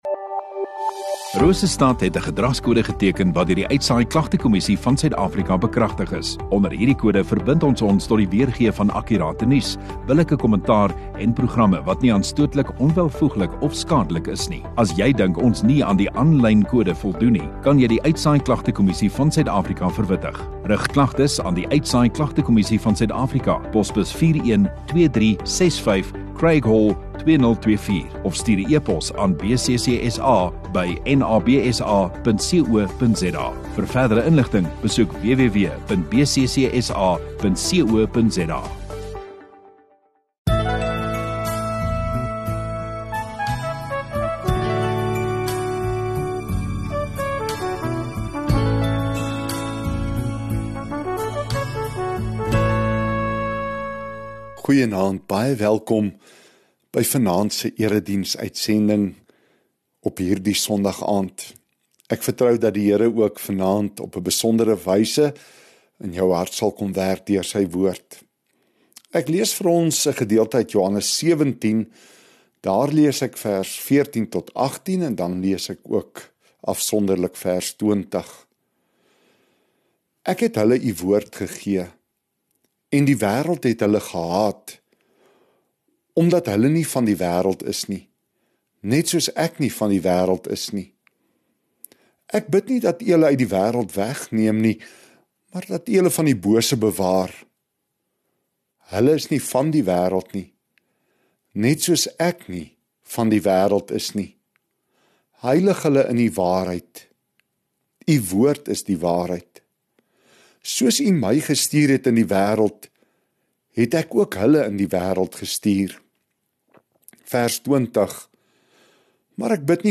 27 Jul Sondagaand Erediens